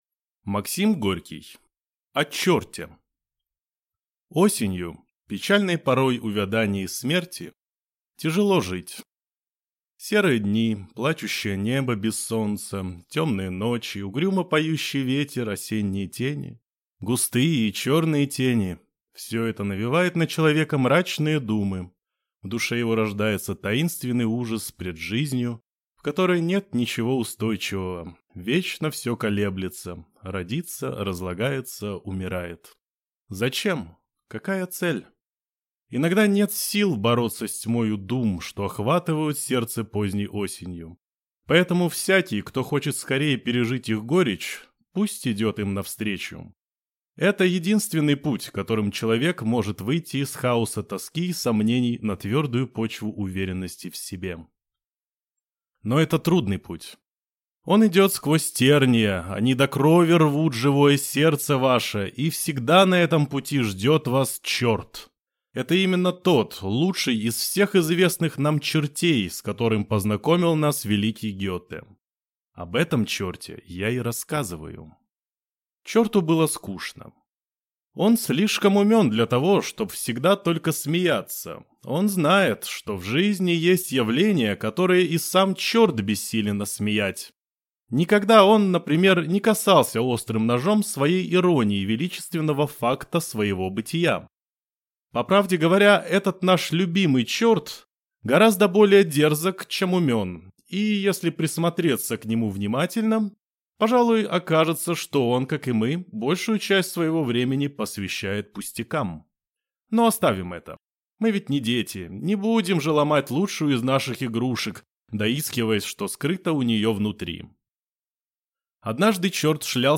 Аудиокнига О чёрте | Библиотека аудиокниг